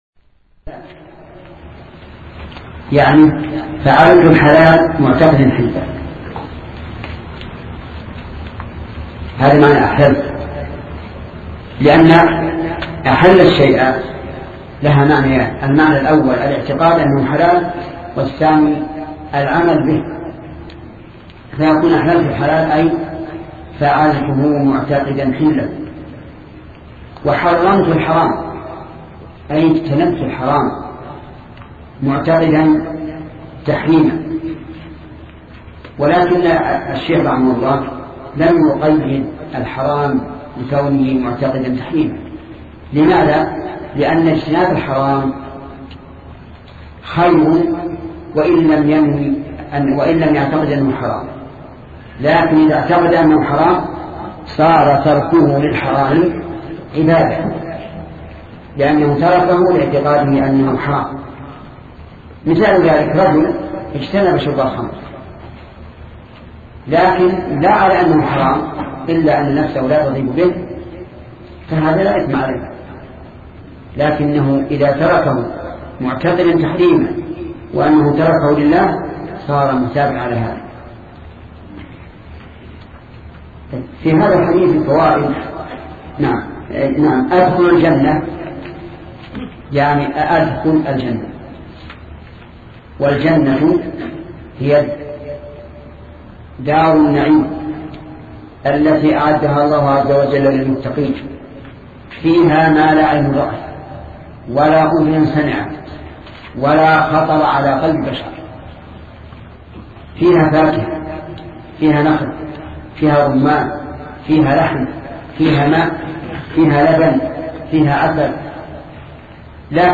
سلسلة مجموعة محاضرات شرح الأربعين النووية لشيخ محمد بن صالح العثيمين رحمة الله تعالى